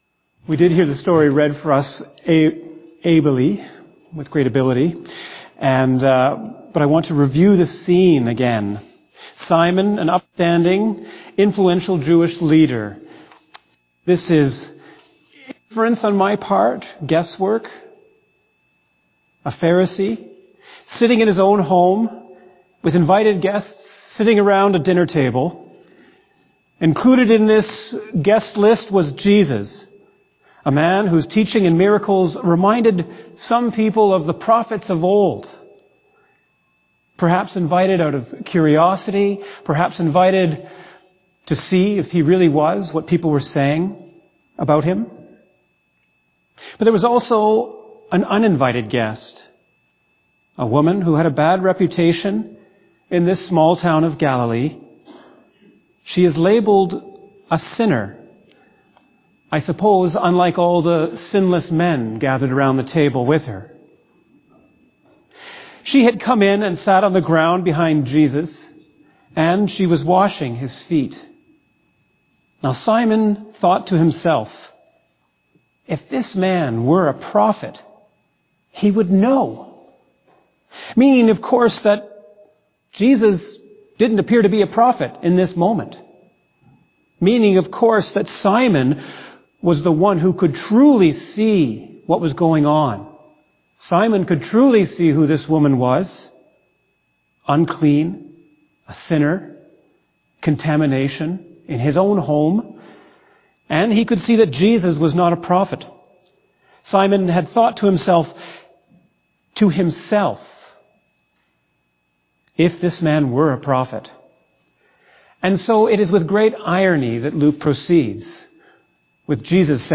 fbcsermon_2025_Feb9.mp3